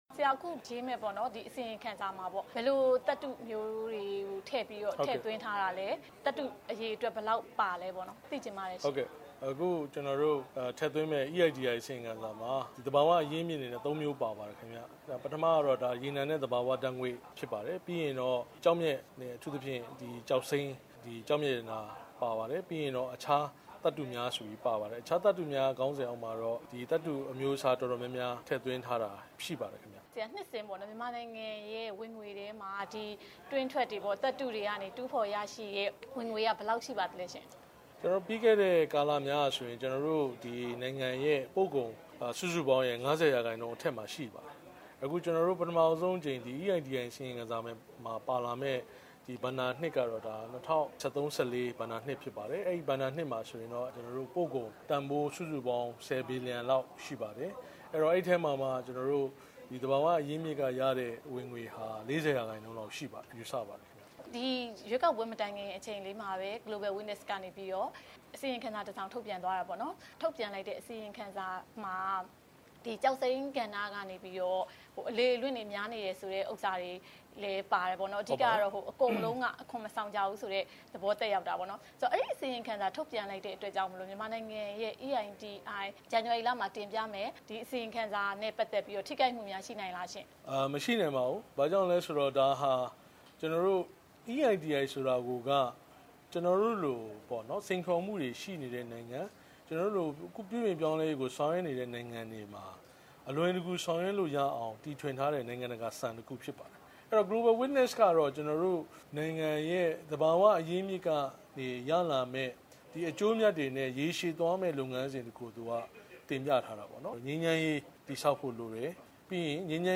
MEITI အဖွဲ့က ထုတ်ပြန်မယ့် အစီရင်ခံစာအကြောင်း မေးမြန်းချက်